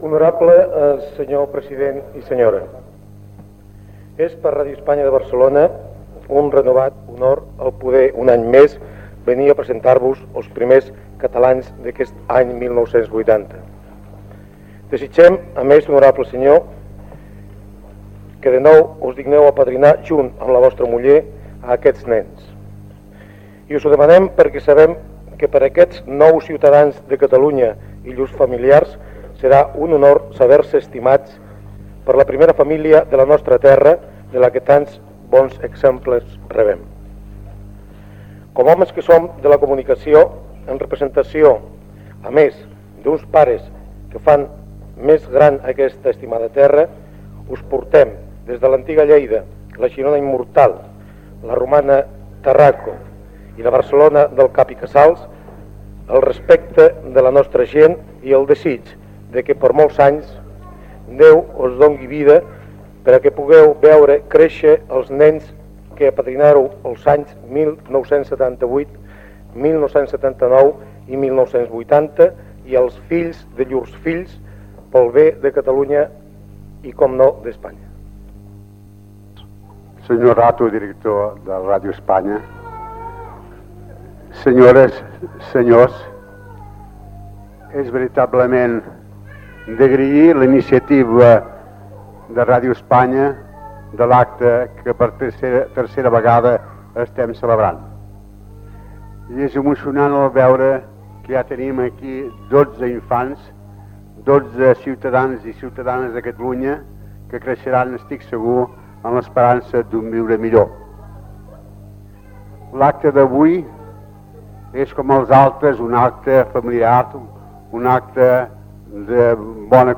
en l'acte d'apadrinament dels primers nadons nascuts a Catalunya l'any 1980 a Barcelona, Tarragona, Lleida i Girona